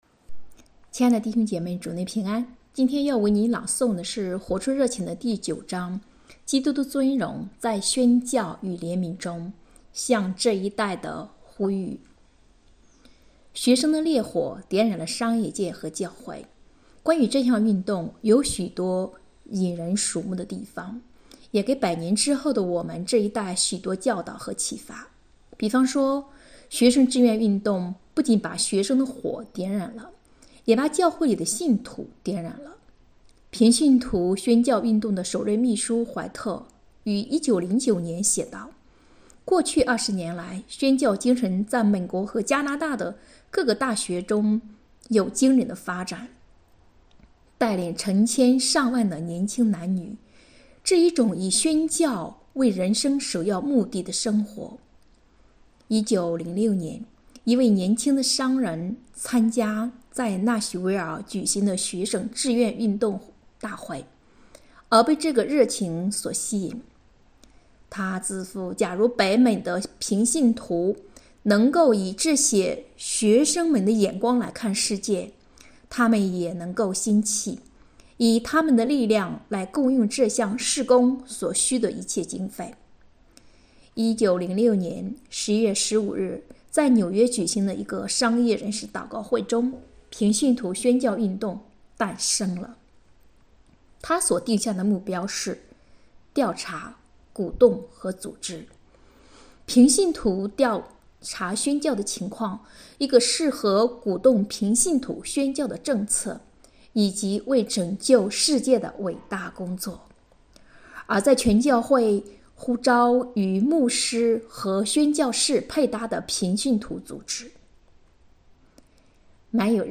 2024年9月26日 “伴你读书”，正在为您朗读：《活出热情》 欢迎点击下方音频聆听朗读内容 音频 https